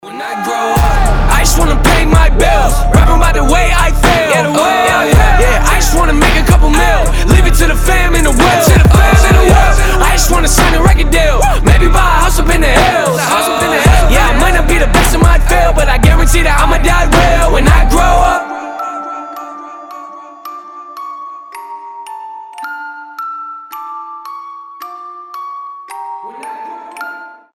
• Качество: 320, Stereo
мужской вокал
громкие
Хип-хоп
качающие
Rap